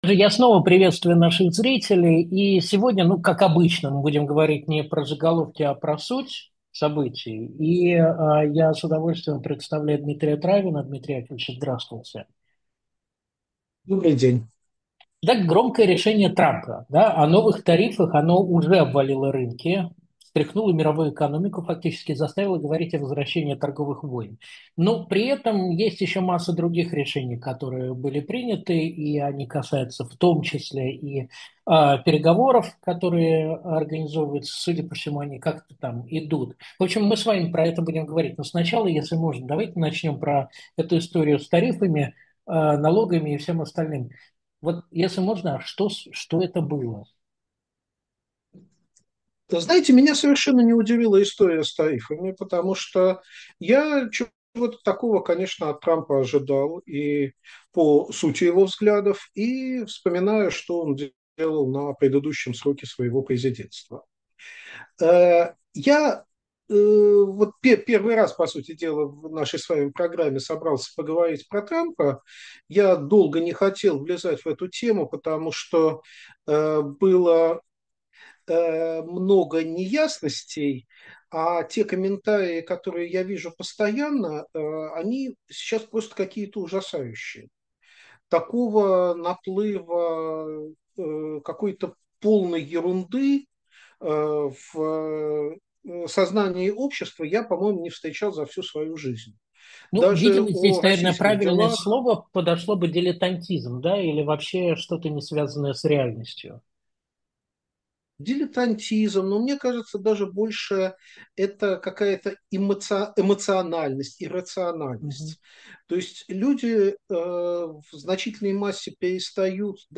В разговоре мы анализируем не только внутреннюю политику США, но и международный контекст: роль Китая, Европы, перспективы переговоров по Украине, будущее мировой торговли и что нас всех ждёт в новой глобальной реальности.